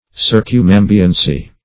Search Result for " circumambiency" : The Collaborative International Dictionary of English v.0.48: Circumambiency \Cir`cum*am"bi*en*cy\, n. The act of surrounding or encompassing.
circumambiency.mp3